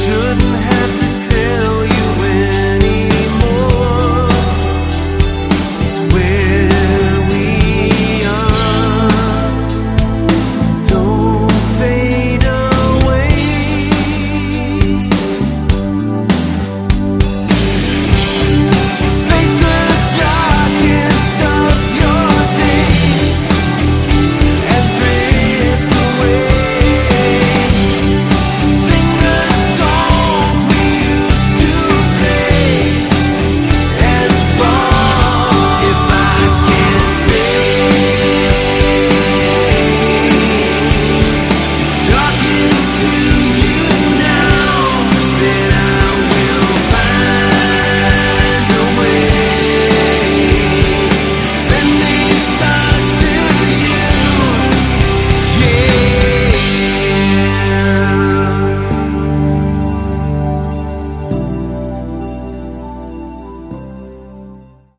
Category: AOR
lead and backing vocals, bass
guitars
keyboards
drums, percussion